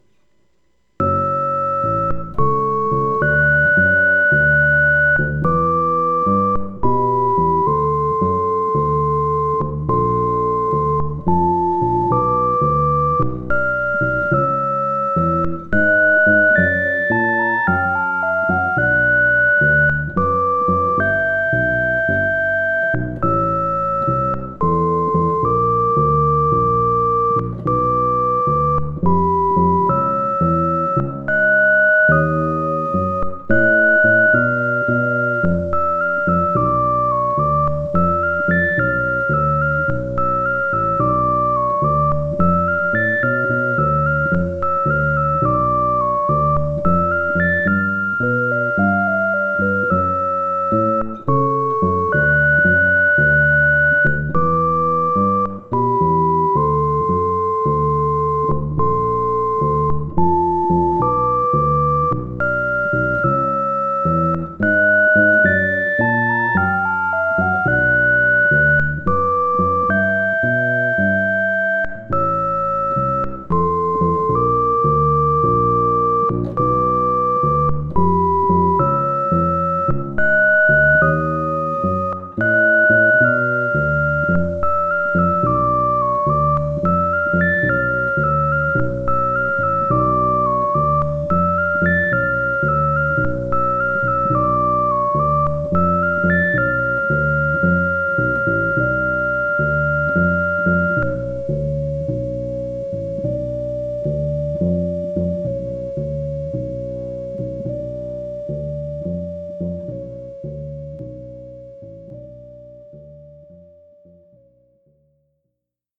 [♪] The Theory of Structured Procrastination Arduino Piano2 & Bass '21.05.04 宅録
2声のメロディーをプログラムで演奏させてます。 さらにベースを重ねてみました。